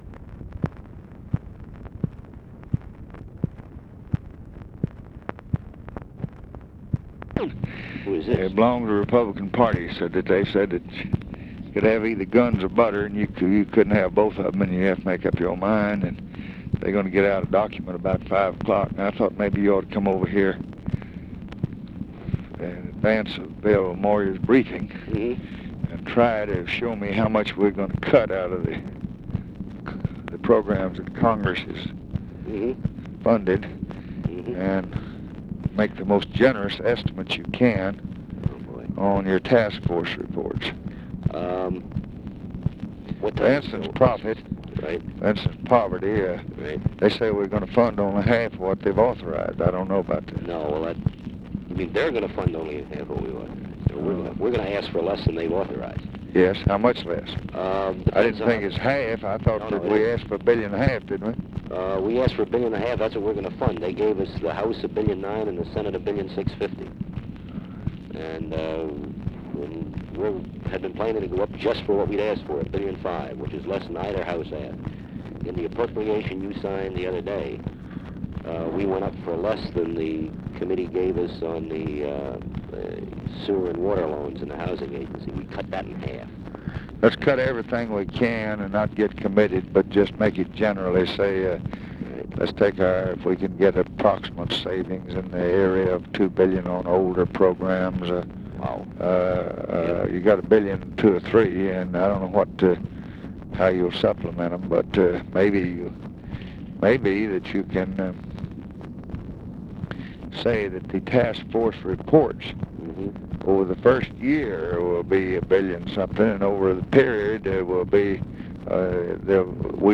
Conversation with CHARLES SCHULTZE and OFFICE CONVERSATION, August 30, 1965
Secret White House Tapes